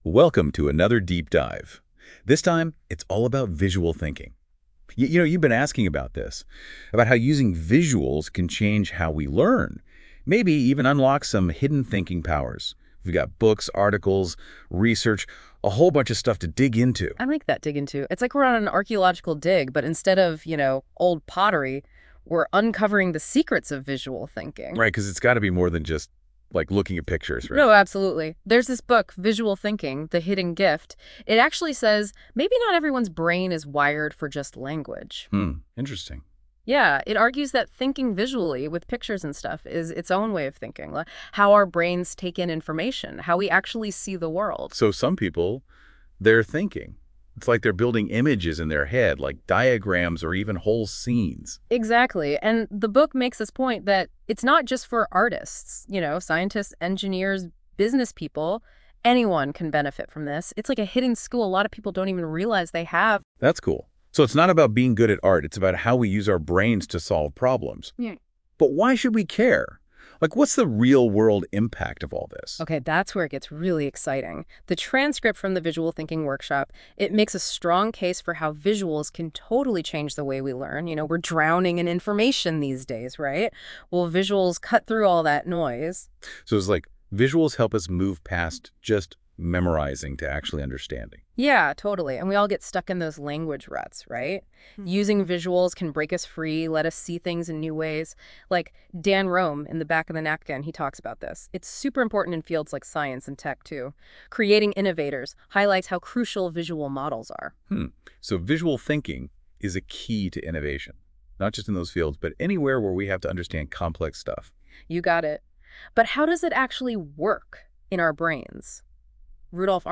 Discover the benefits and applications of Visual Thinking in this AI-Generated Podcast. While machine-produced, the content is thoughtfully curated from 40 of my favorite books and articles. This engaging 36-minute deep dive explores the many facets of Visual Thinking.